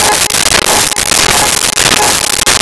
Car Alarm In Parking Structure
Car Alarm in Parking Structure.wav